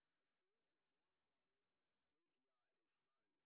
sp24_street_snr0.wav